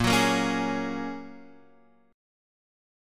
Bbm7#5 chord